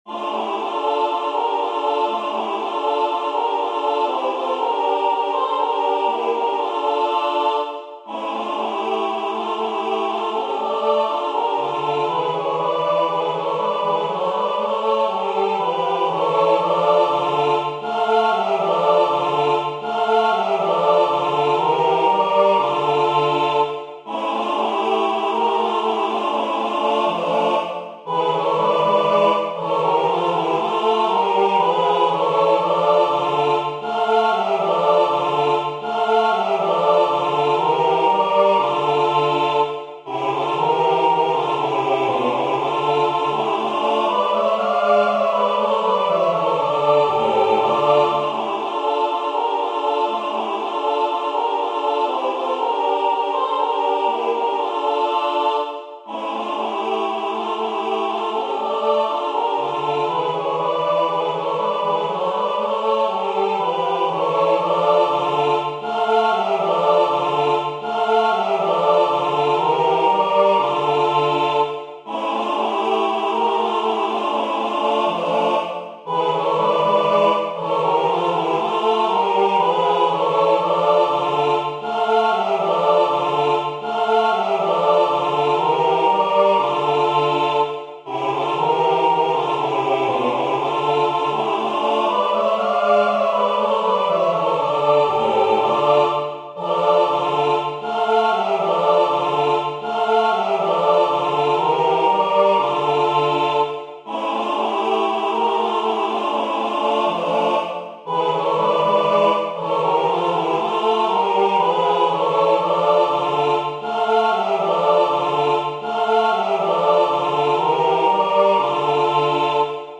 CHÓR